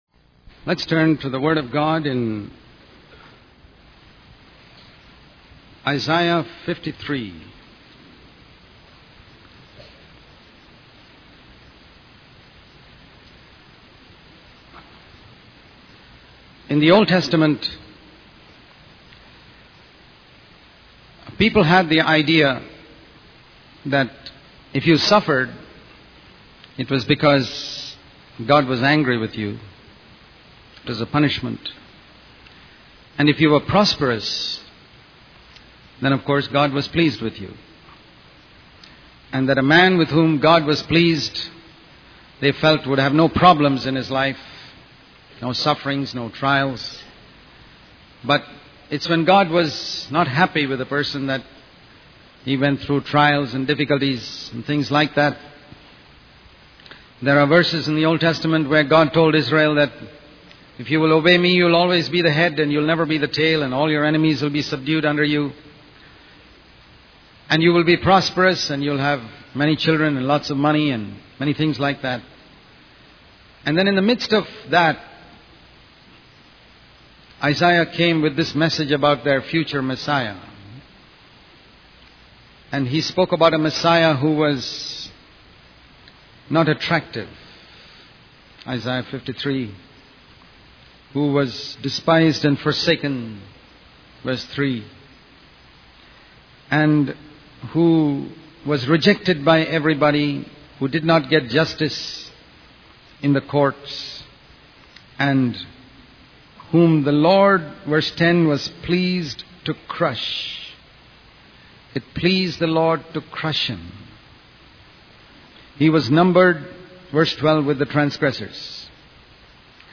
In this sermon, the preacher discusses the story of Jacob from the book of Genesis.